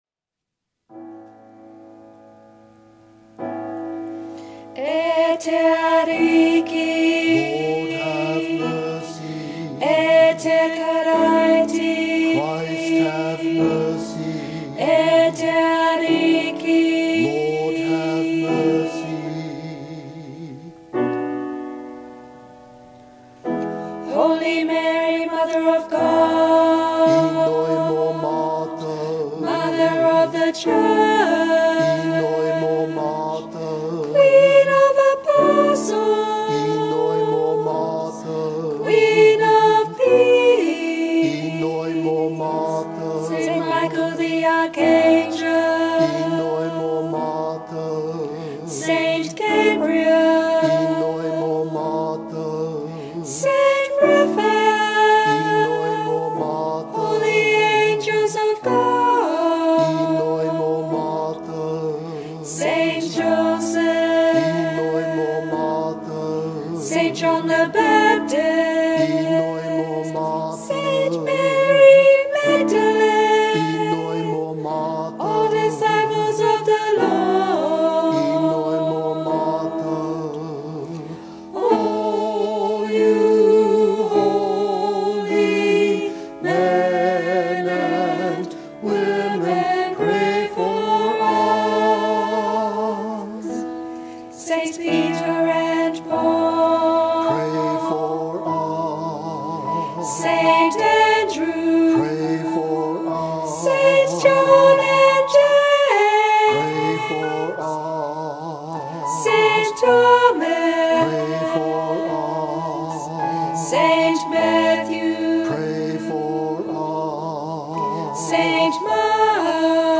This resource will be supplemented with a sung recording that can be used in prayer while you listen.